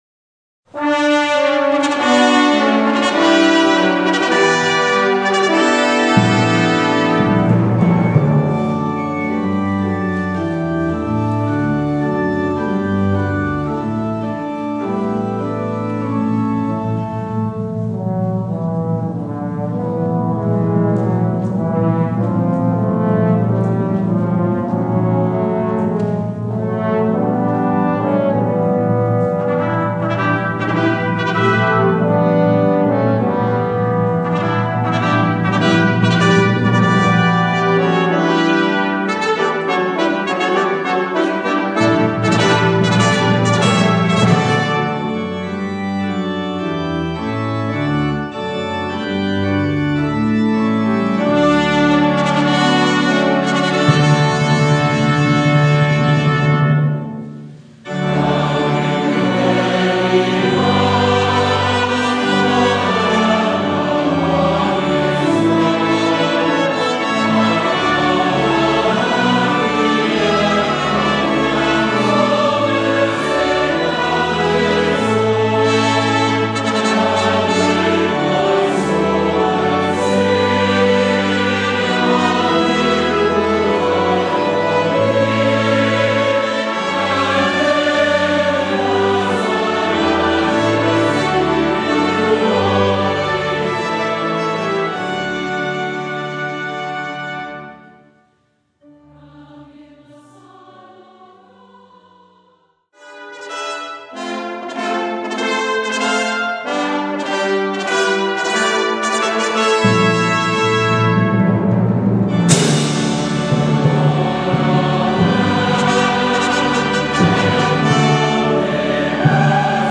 Voicing: Congregation